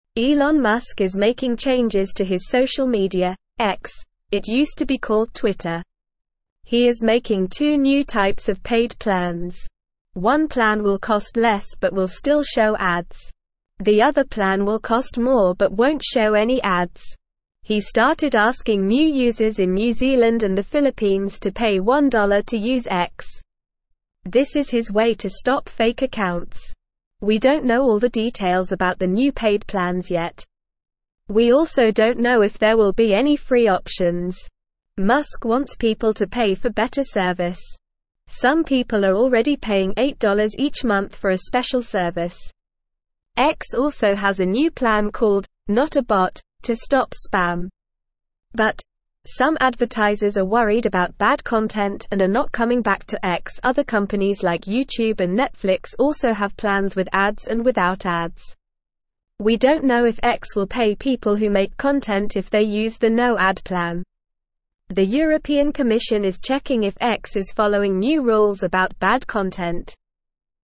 Normal
English-Newsroom-Beginner-NORMAL-Reading-Elon-Musks-Social-Media-X-Introduces-Paid-Plans.mp3